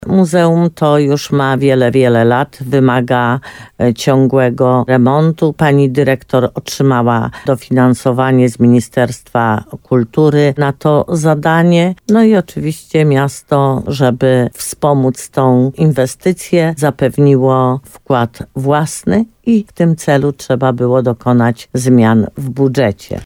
– mówi burmistrz Limanowej, Jolanta Juszkiewicz.